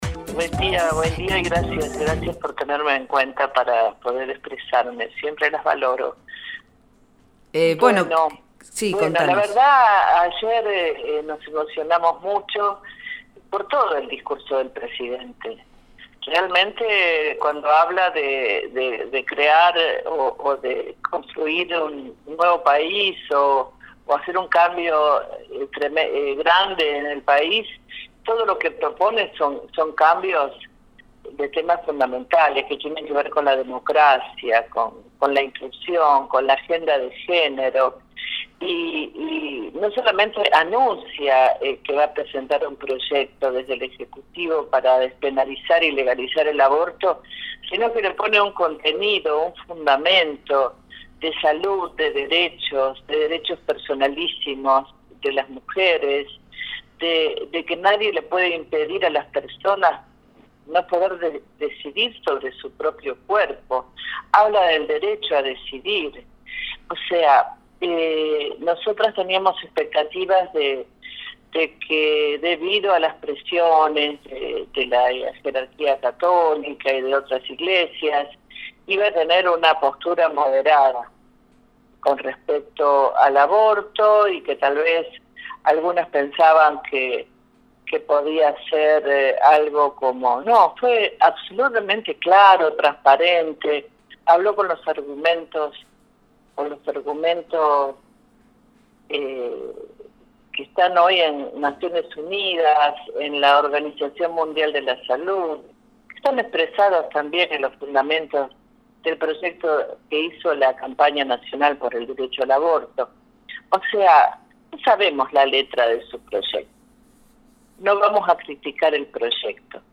En diálogo con Proyecto Erre se expresó a propósito del discurso de Alberto Fernández en el que anunció que enviará al Congreso de la Nación, en los próximos 10 dias, un proyecto de Ley para legalizar el aborto en Argentina.